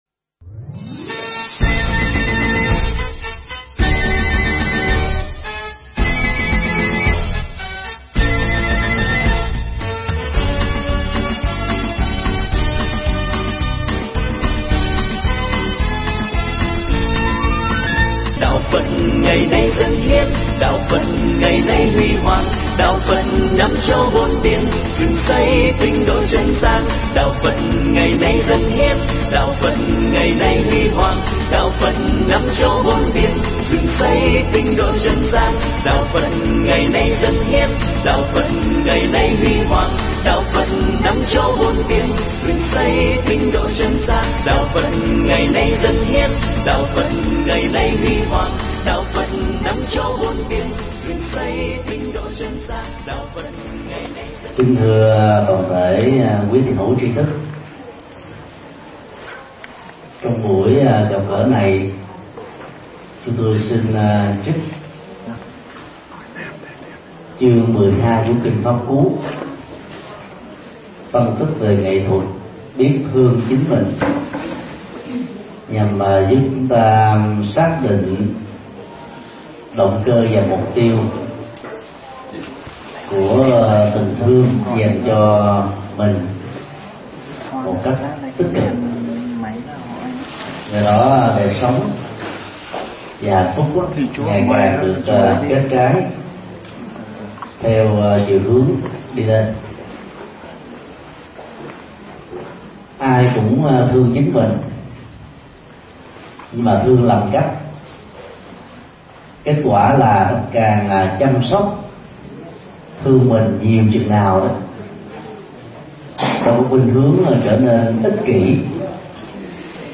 Nghe Mp3 thuyết pháp Kinh Pháp Cú 12: Biết thương chính mình – Thích Nhật Từ
Tải mp3 Thuyết Giảng Kinh Pháp Cú 12: Biết thương chính mình do Thầy Thích Nhật Từ giảng tại chùa Bát Mẫu, Hà Nội, ngày 08 tháng 04 năm 2011